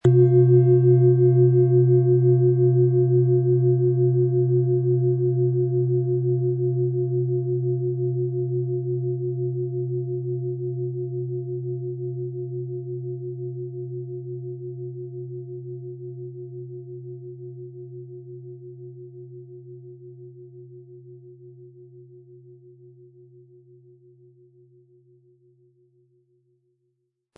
Wie klingt diese Planetenton-Klangschale Sonne?
Um den Original-Klang genau dieser Schale zu hören, lassen Sie bitte den hinterlegten Sound abspielen.
PlanetentonSonne
MaterialBronze